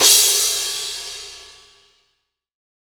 Percs
DJP_PERC_ (4).wav